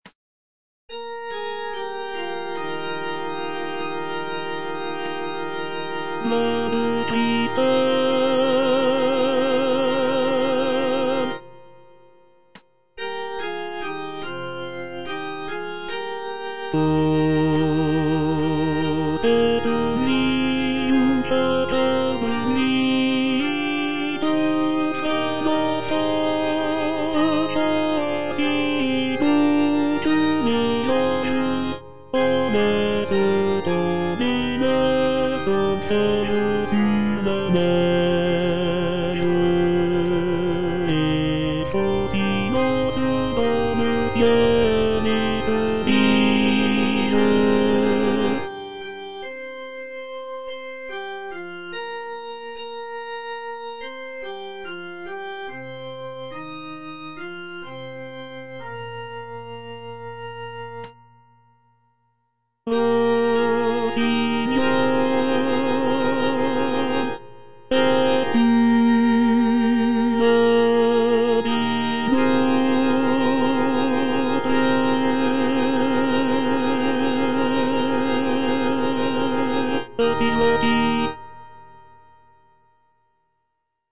Tenor (Voice with metronome)